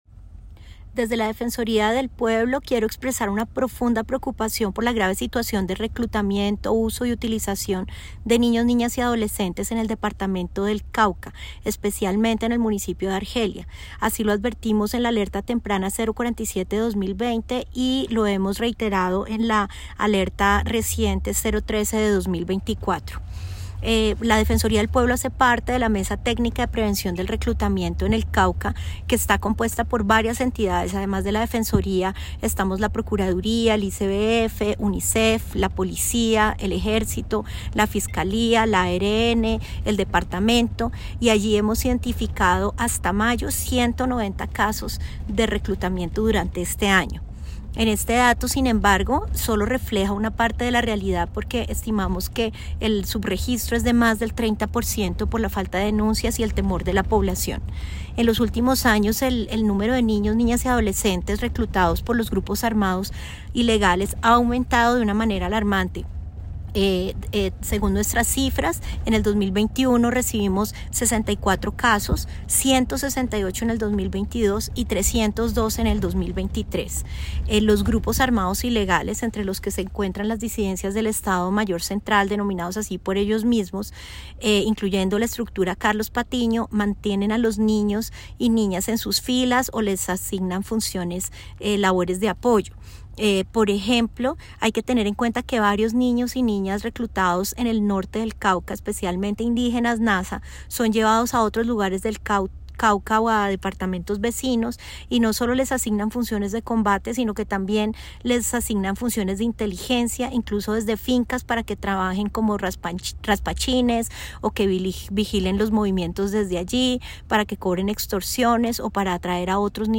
Escuche el pronunciamiento de la Defensora del Pueblo